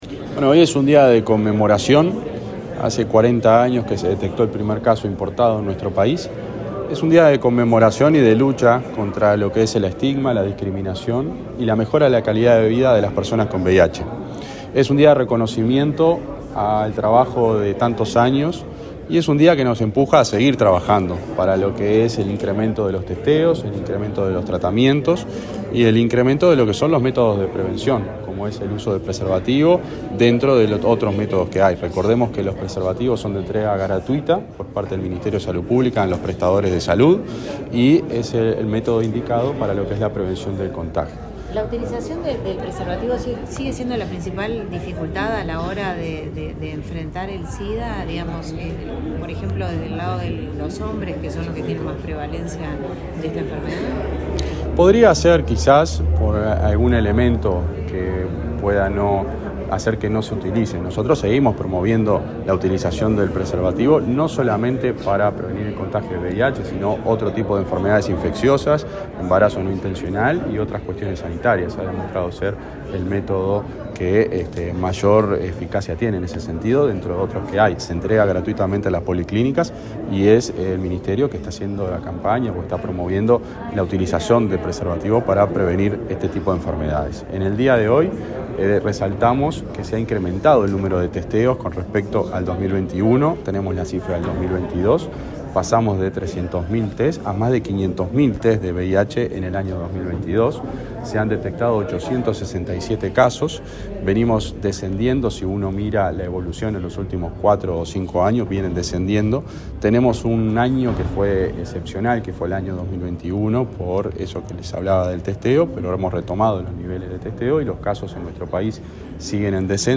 Declaraciones del subsecretario de Salud Pública
Declaraciones del subsecretario de Salud Pública 27/07/2023 Compartir Facebook X Copiar enlace WhatsApp LinkedIn Este jueves 27, en el Ministerio de Salud Pública, el subsecretario de la cartera, José Luis Satdjian, participó en el acto por el Día Nacional de Lucha contra el VIH/Sida. Luego dialogó con la prensa.